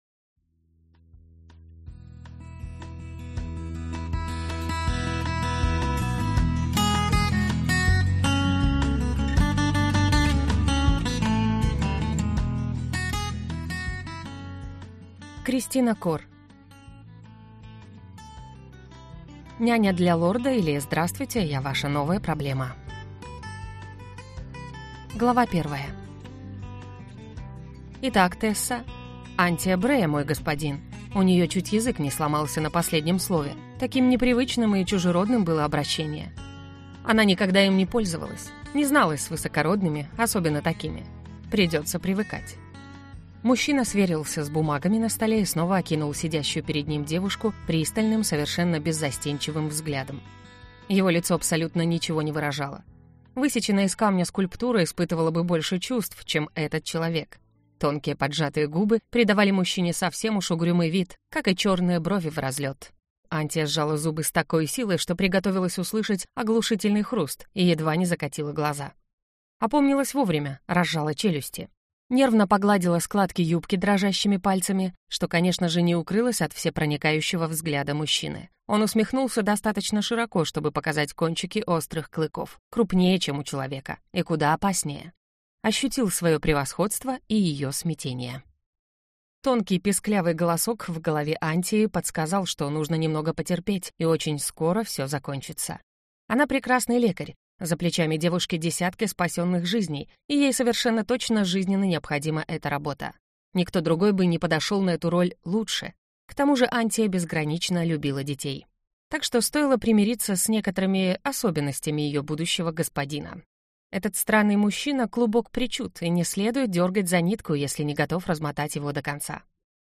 Аудиокнига Няня для Лорда, или Здравствуйте, я ваша новая проблема!